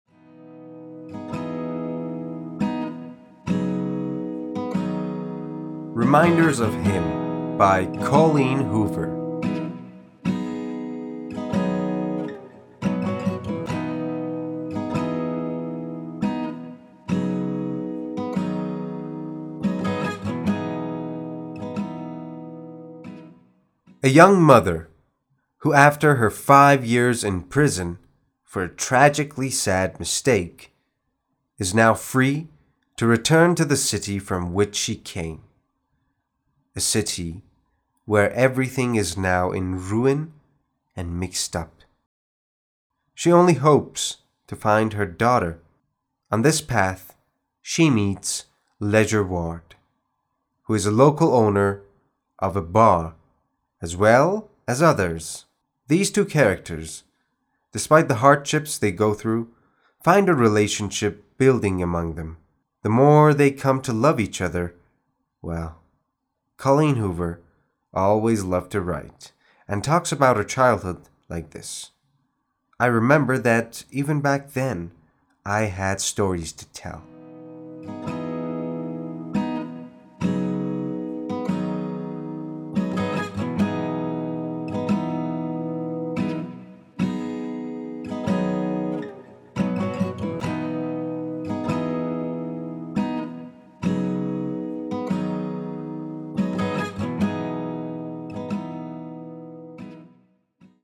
معرفی صوتی کتاب Reminders of Him